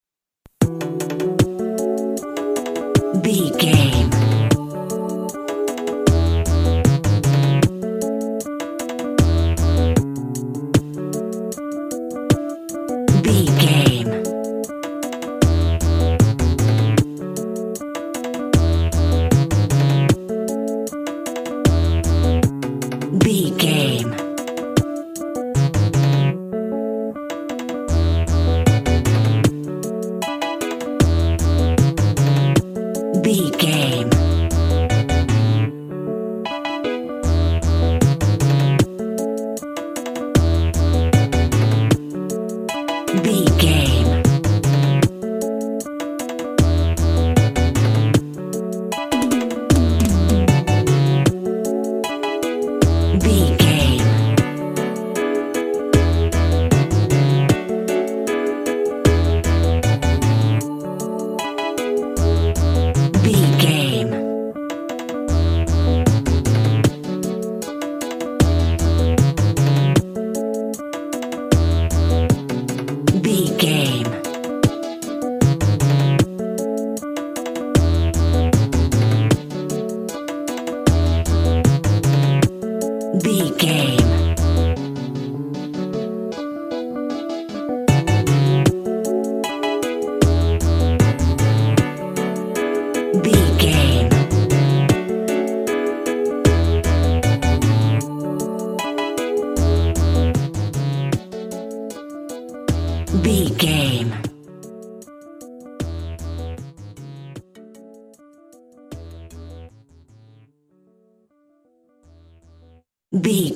Also with small elements of Dub and Rasta music.
Ionian/Major
drums
bass
guitar
piano
brass
pan pipes
steel drum